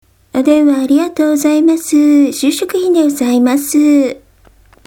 管理者「なんだか暗いし棒読みになっているから、もっと気持ちを込めて」